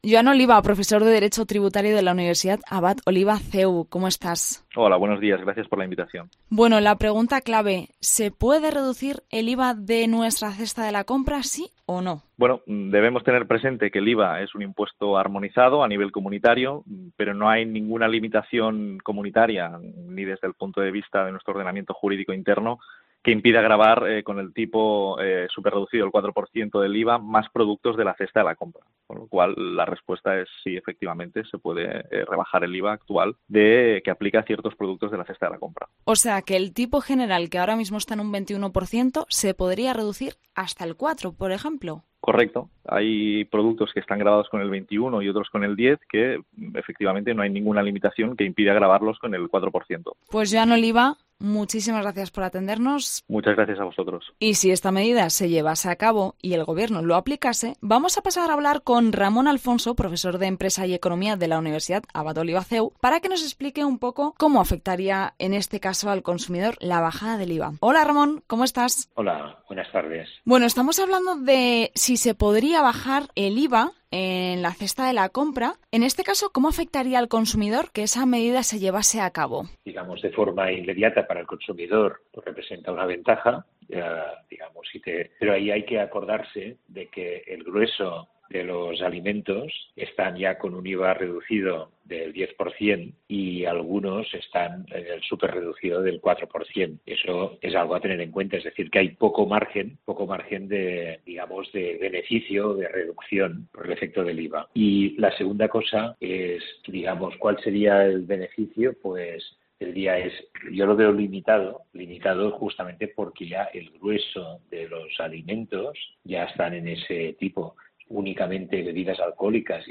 Analizamos con dos expertos si es posible rebajar el IVA del precio de la cesta de la compra y cómo afectaría esta aplicación a los consumidores